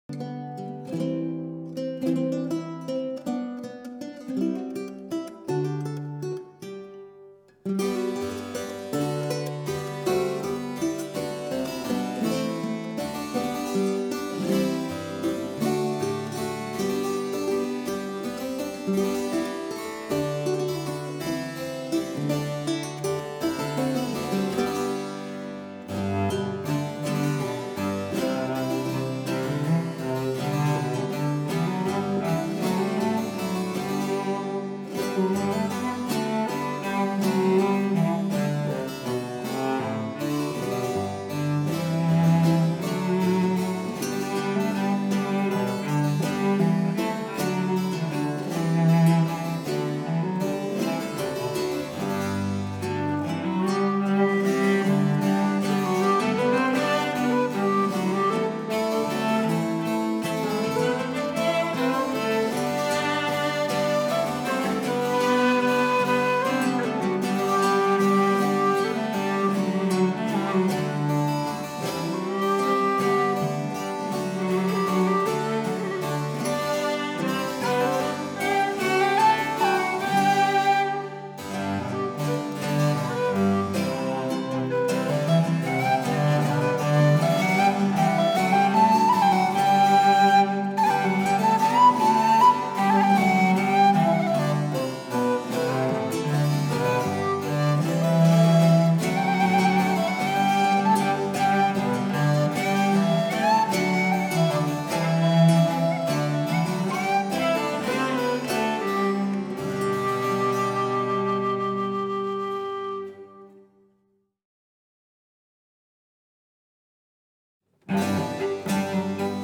Susato Whistles
Cello
Harpsichord
Baroque and Classical guitar